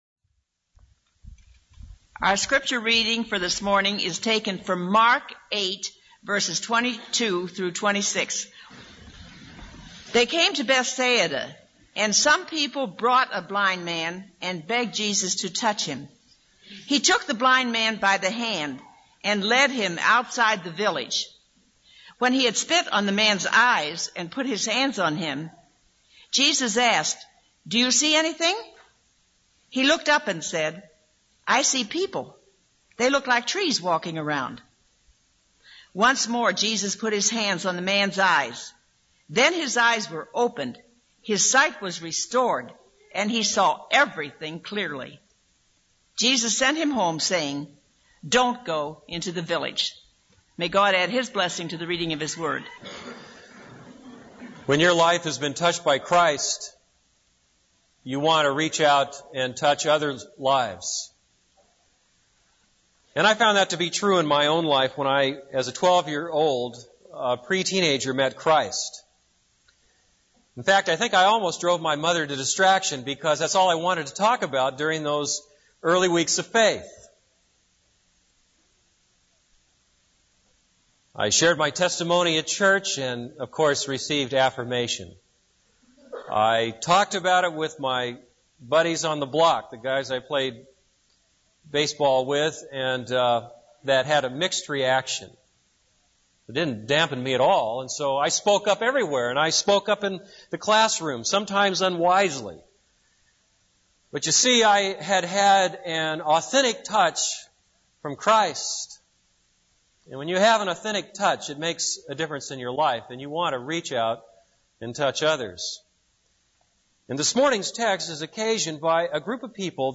This is a sermon on Mark 8:22-26.